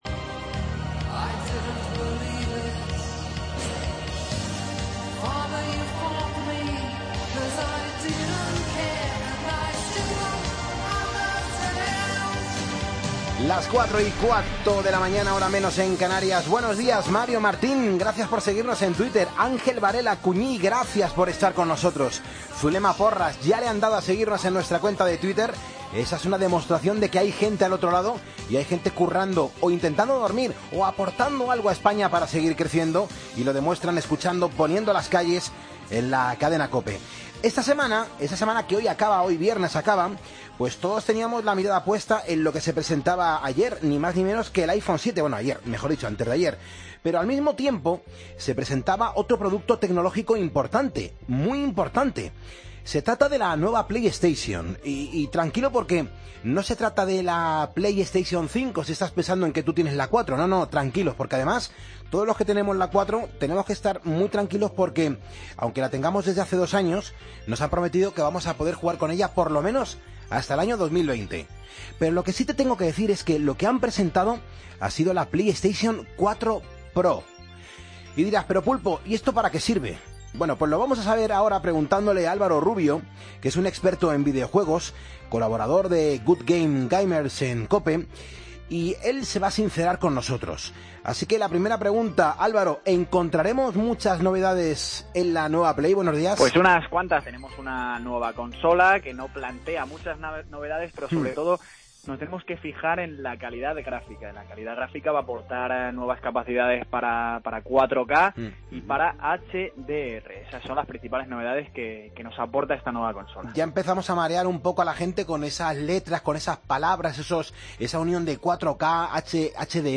Entrevistas en Poniendo las calles